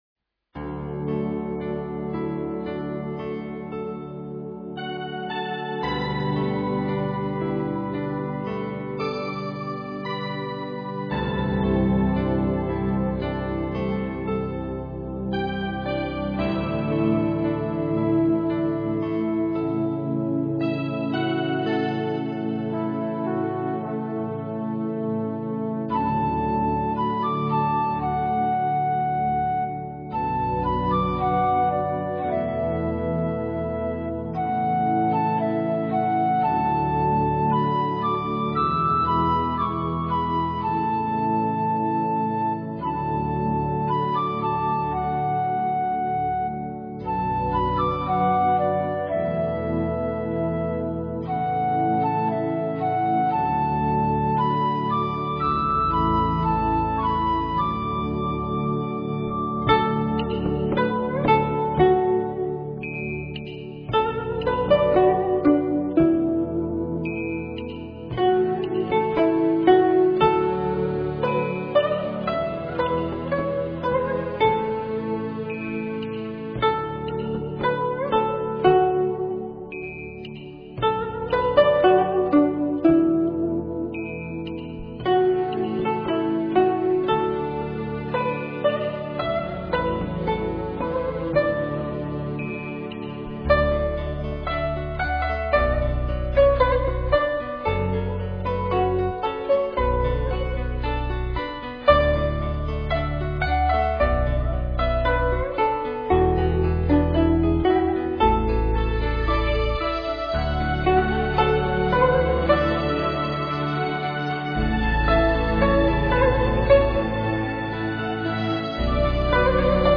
背景音乐 古筝 山水一般闲